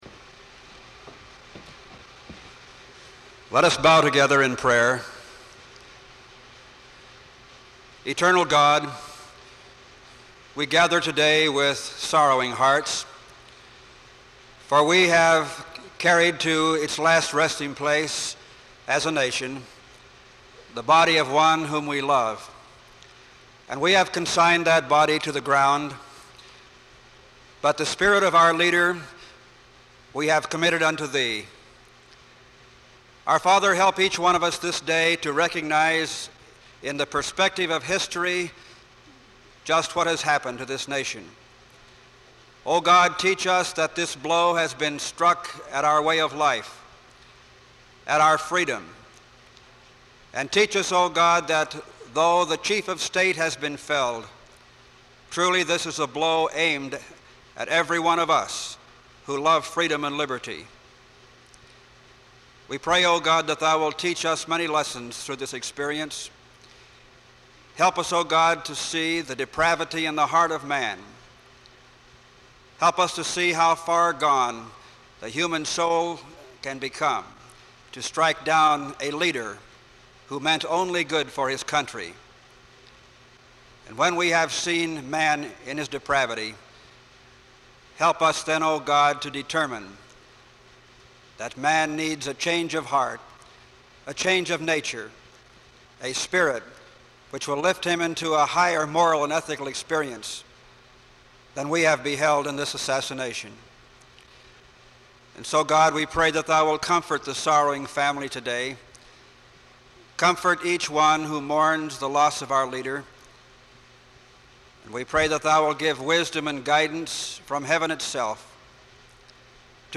John F. Kennedy memorial service at Cal Poly, November 26, 1963
Opening prayer invocation
Benediction
Playing of the National Anthem
Open reel audiotape